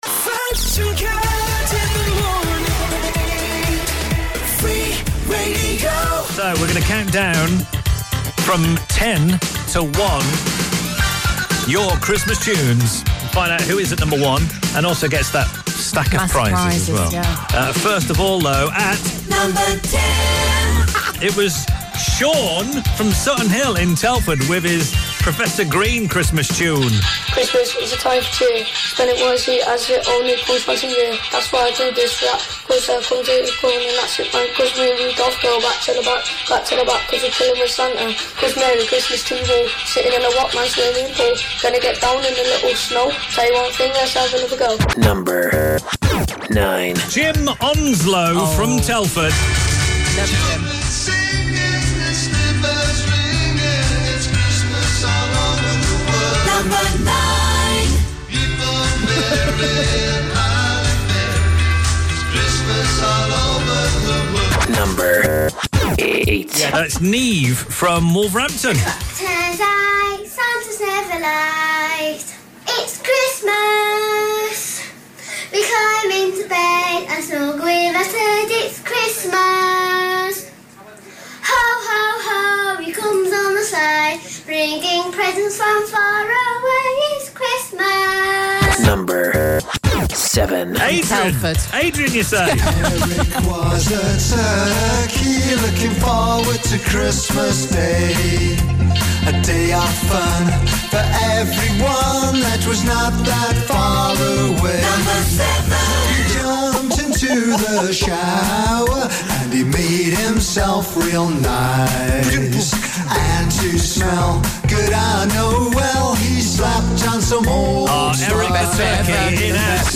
Thank you so much for recording your own Christmas songs in for our Christmas Countdown!